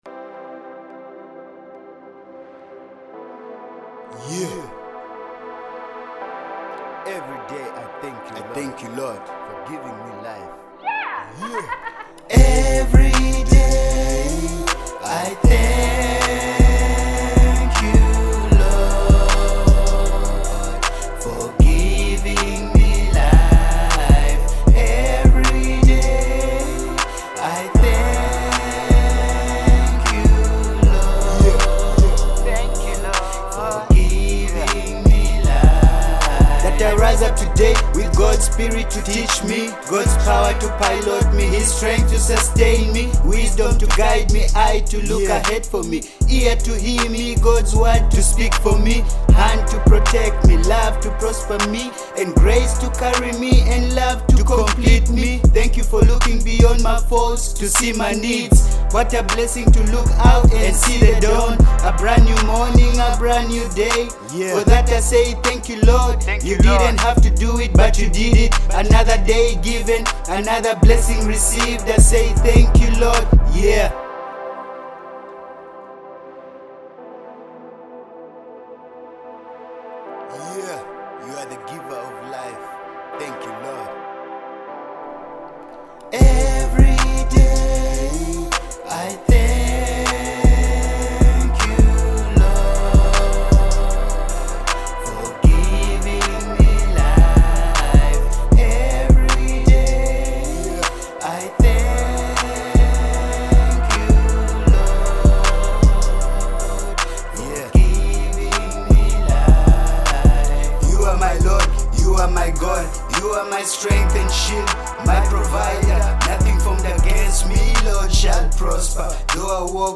My new track a prayer song I Thank u lord